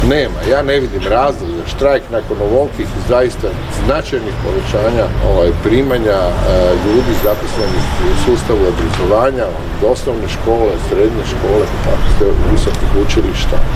Sindikati traže materijalna i nematerijalna prava, a resorni ministar Radovan Fuchs je poručio: